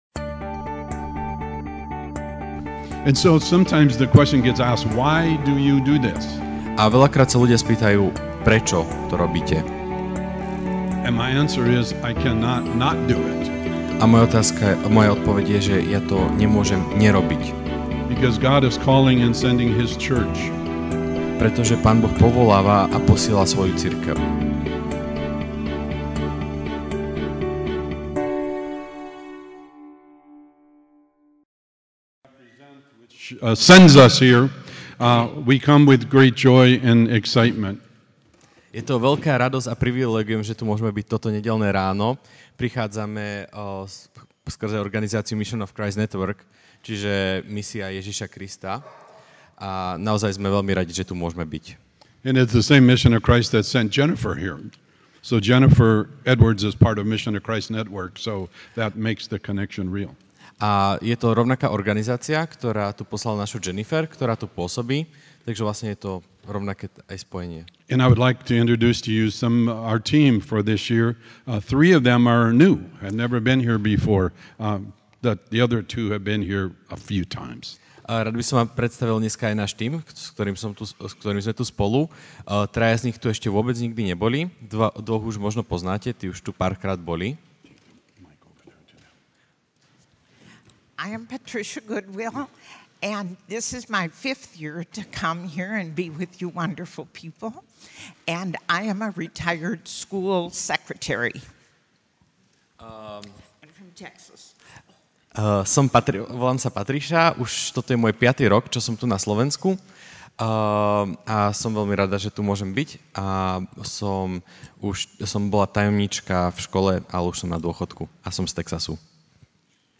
Ranná kázeň: Kristova misia: Na misii (Ž 92, 13-16)Spravodlivý bude prekvitať sťa palma a vyrastie ako libanonský céder.